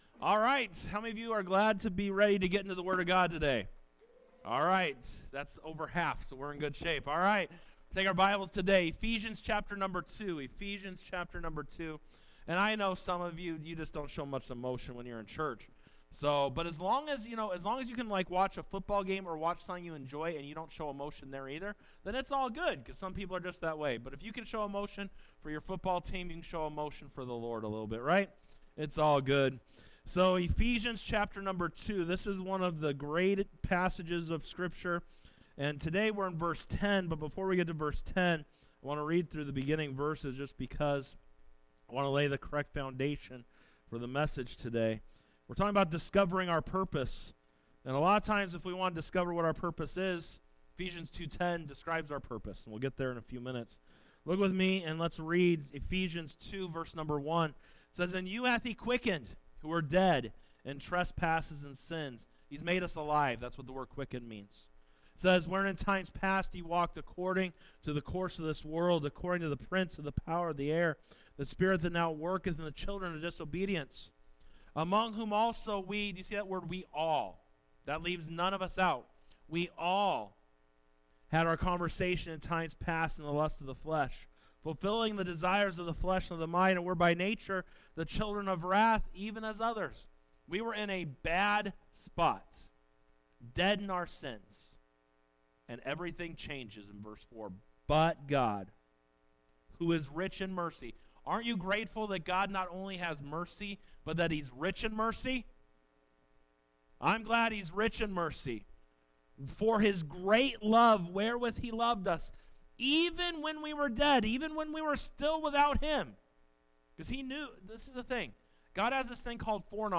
Sermons | Victory Baptist Church
Sunday Worship Service 01:12:25 - Discovering Your Purpose Part 2: "God's Masterpiece" by vbcchino